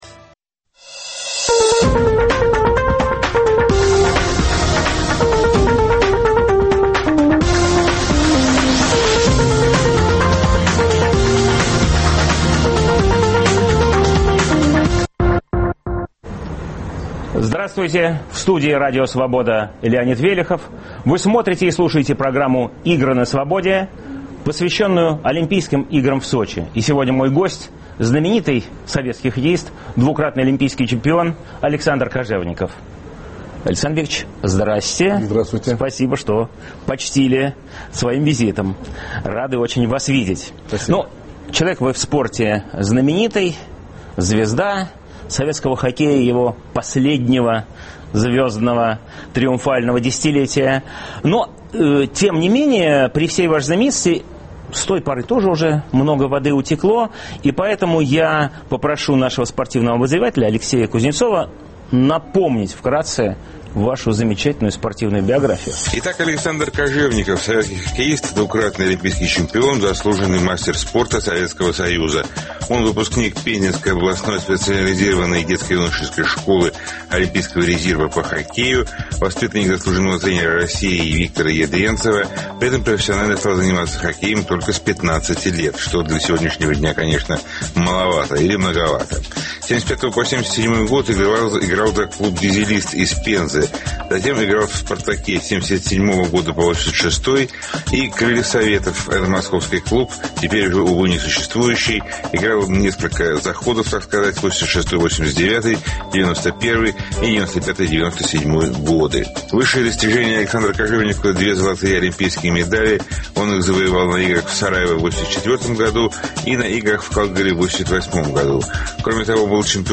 Александр Кожевников. Хоккеист и отец. Интервью с хоккеистом, двукратным олимпийским чемпионом Александром Кожевниковым.